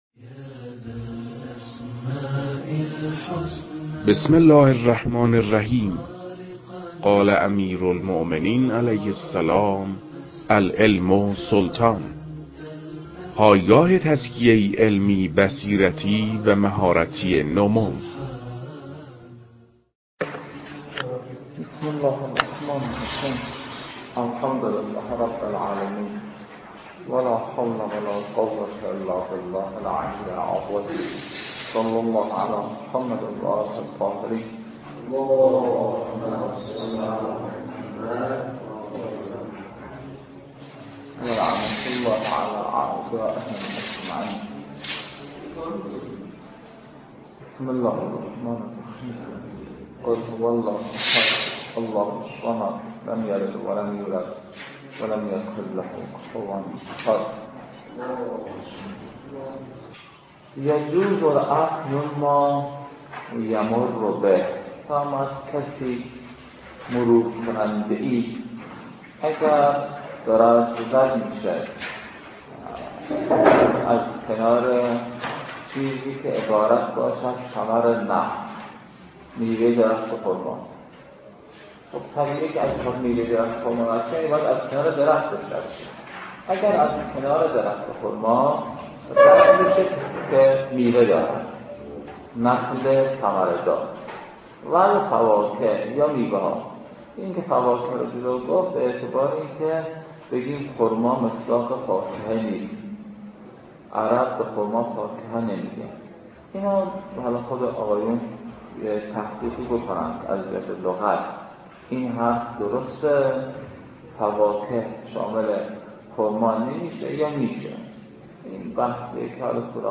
این فایل، تدریس بخشی از کتاب شرح لمعه